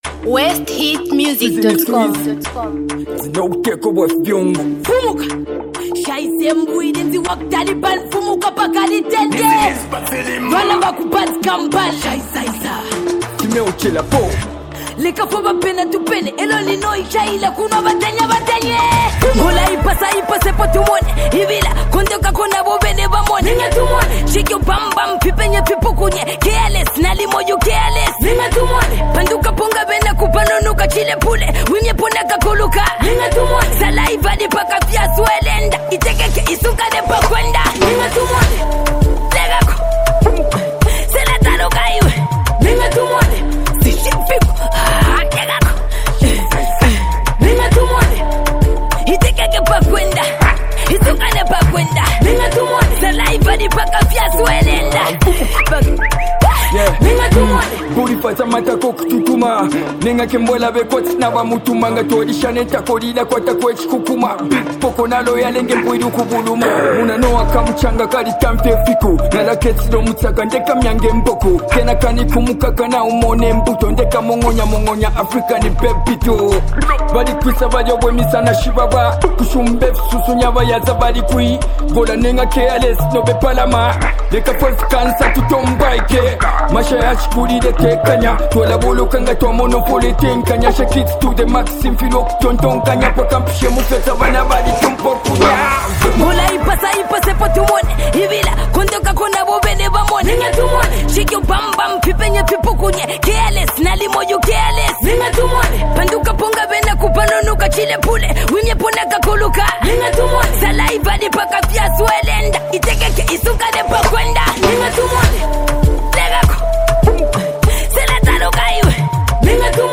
the best female rapper in Zambia
the talented singer
powerful rap verses
melodious vocals